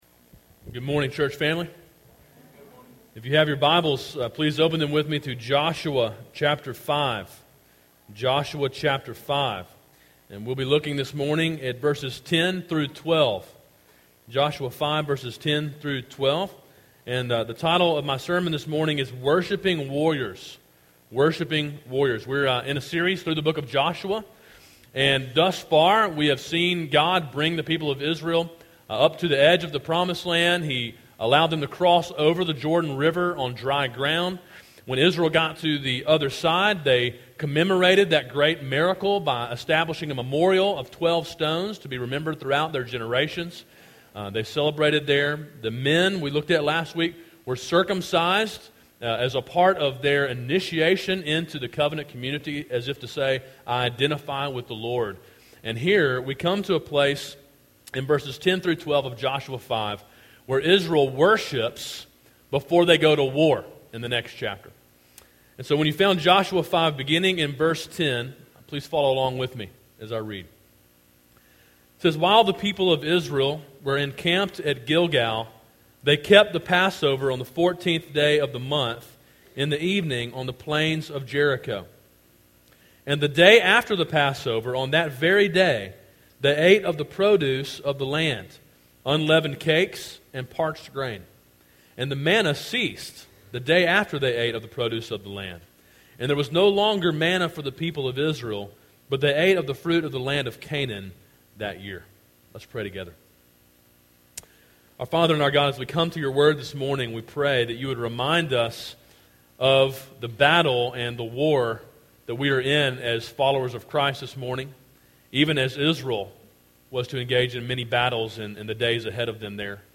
A sermon in a series on the book of Joshua.